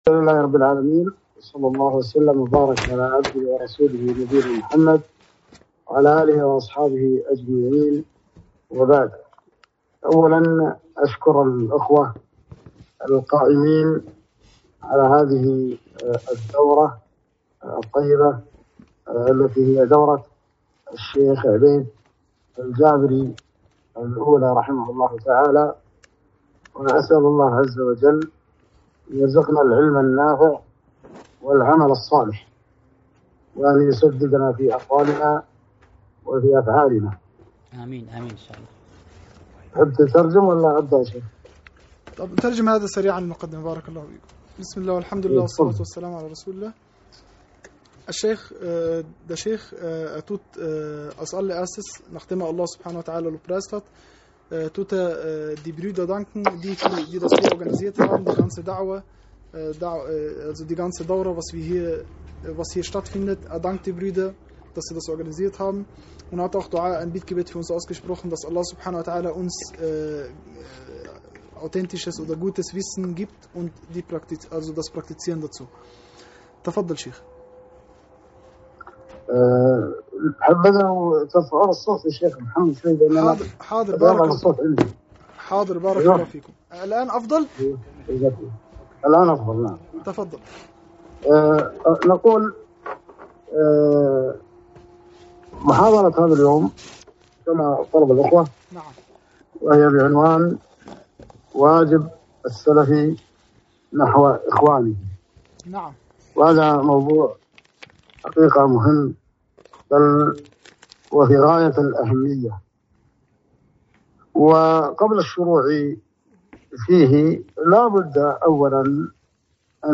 محاضرة - واجب السلفي نحو إخوانه (مترجمة للغة الألمانية)